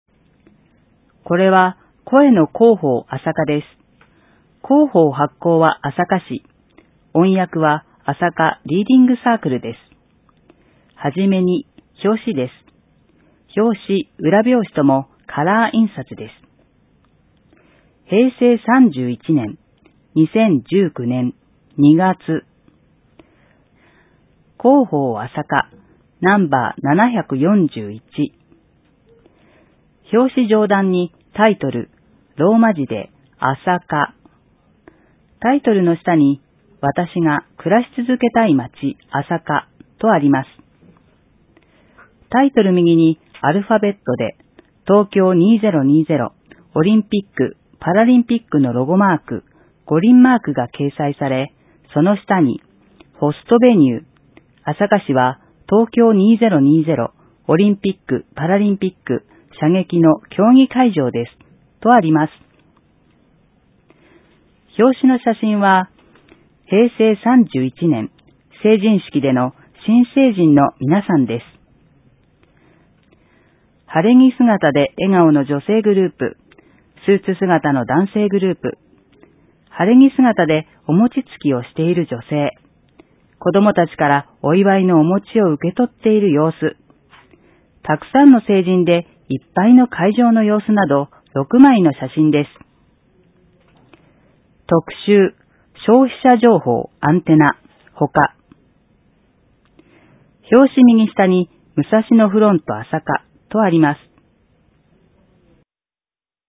｢声の広報あさか」は、市内のボランティア「朝霞リーディングサークル」のご協力で、視覚に障害がある方のご自宅にＣＤ（デイジー形式）を郵送しています。